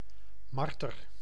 Ääntäminen
Synonyymit wezel muishond Ääntäminen Tuntematon aksentti: IPA: /ˈmɑr.tər/ Haettu sana löytyi näillä lähdekielillä: hollanti Käännös 1. nyuszt 2. nyest Suku: m .